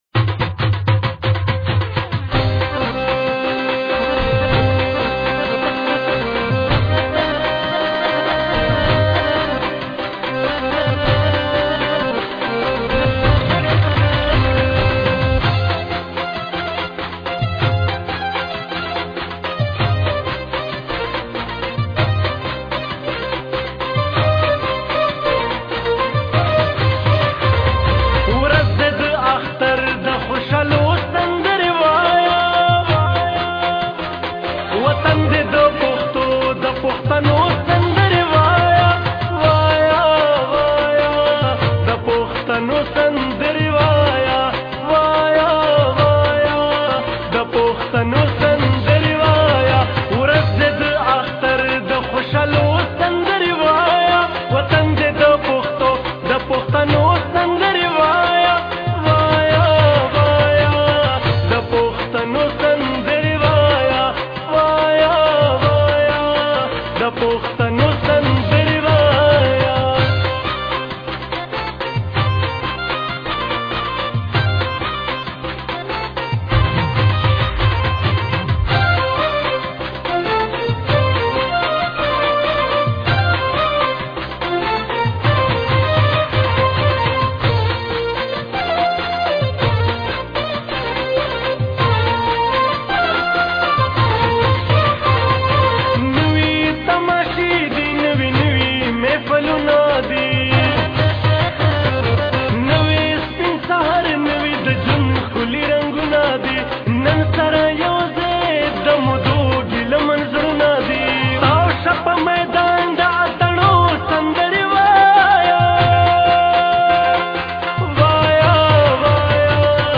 سندره